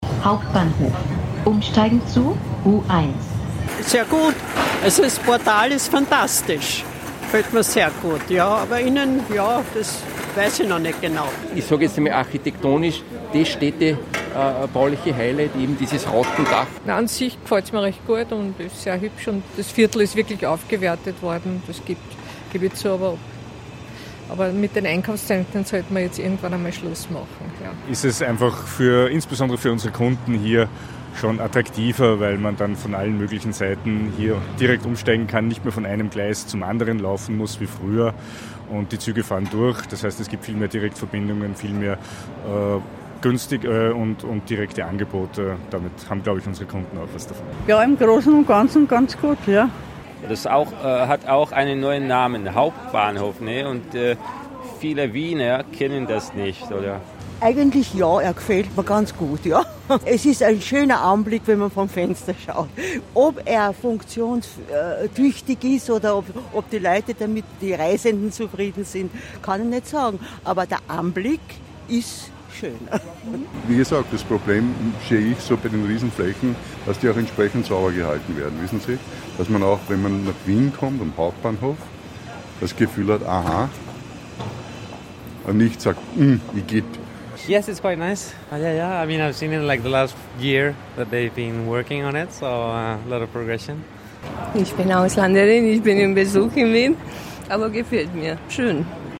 Das sagen Reisende und Anrainer dazu